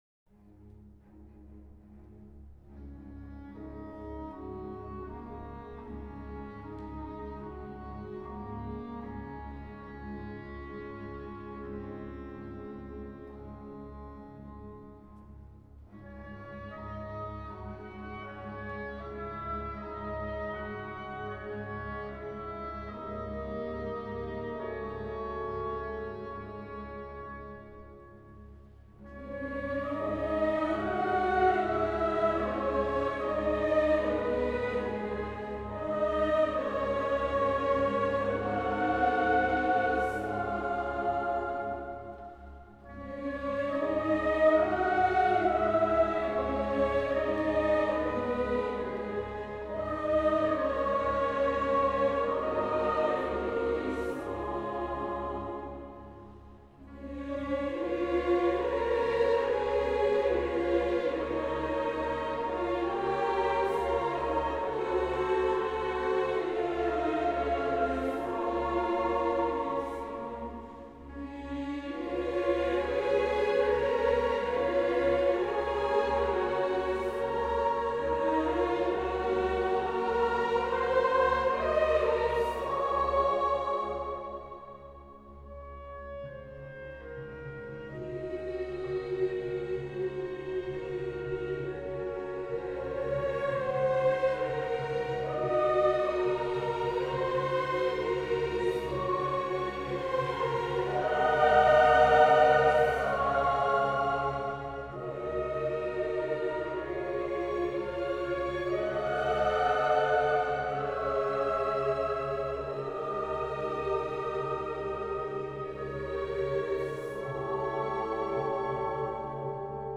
1 Kyrie | Messe Saint-Nicolas – CHORALE CHOEUR TOLOSA